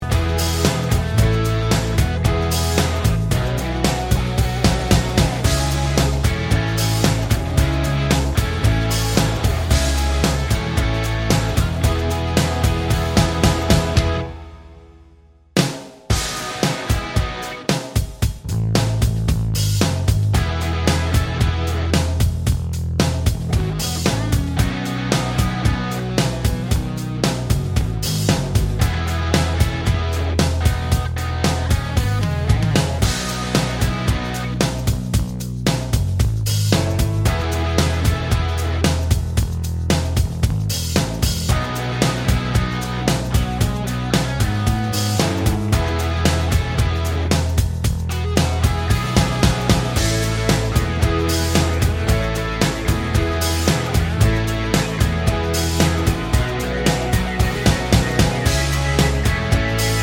no Backing Vocals Rock 3:45 Buy £1.50